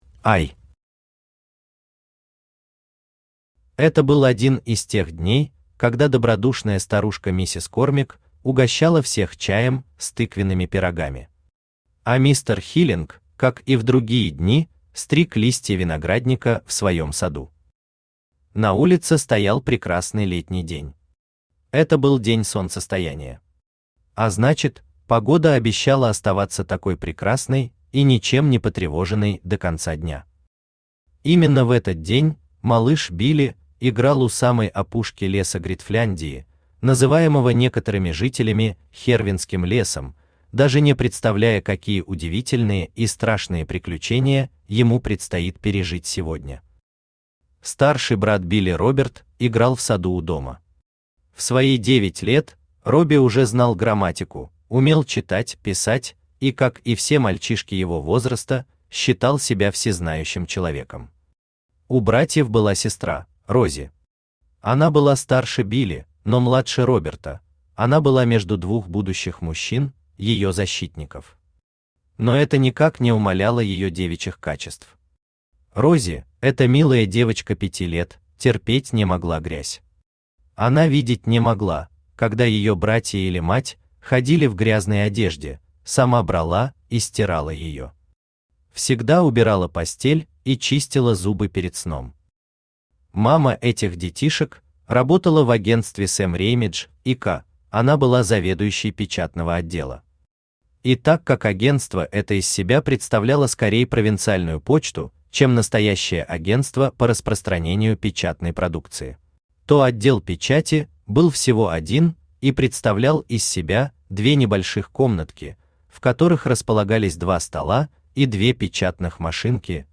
Аудиокнига Дети из Смоллхиллс | Библиотека аудиокниг
Aудиокнига Дети из Смоллхиллс Автор Ильяс Сибгатулин Читает аудиокнигу Авточтец ЛитРес.